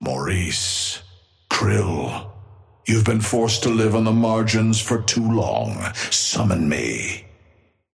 Amber Hand voice line - Maurice.
Patron_male_ally_krill_start_01.mp3